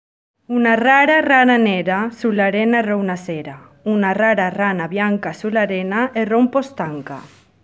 But today I propose to you a few Italian tongue-twisters read by some foreigners who live in Oslo, because I would like you to listen to their different accents and underline the beauty of each.
pronounces a very strong “r” in “rana” and “rara” because  of the Spanish tonic syllable